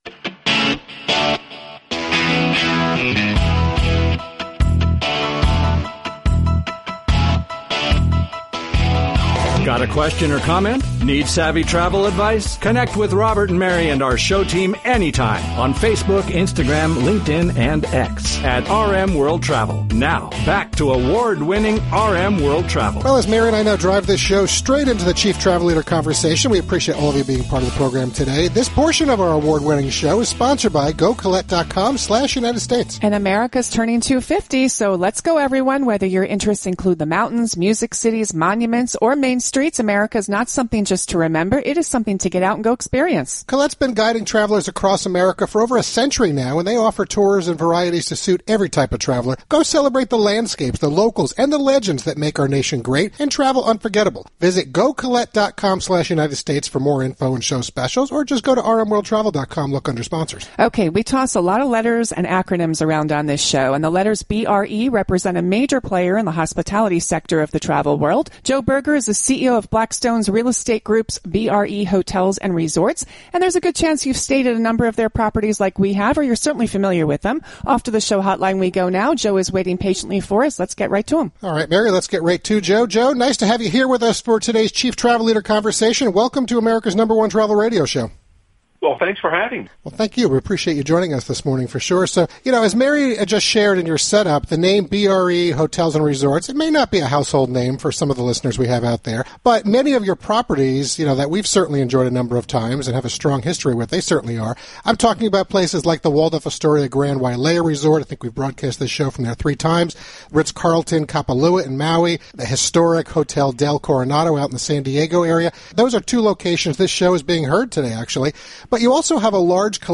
His career in the Hospitality Sector of Travel is impressive and during the September 6th, 2025 national broadcast of America’s #1 Travel Radio Show, we decided to connect with him on-air to discuss personal experiences with some of his leading properties, hear about the company’s growth, memorable moments over his career and more.